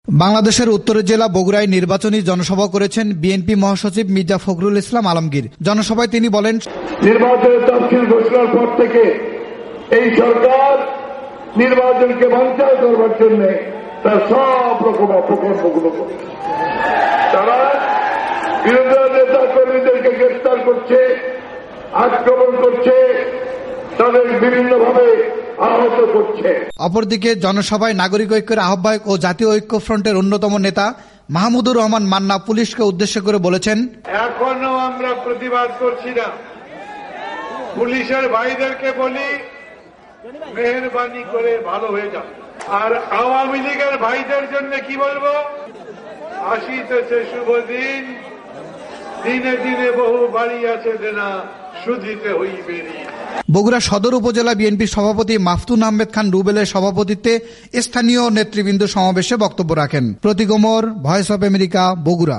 বগুড়ায় মির্জা ফখরুল ইসলামের নির্বাচনী জনসভা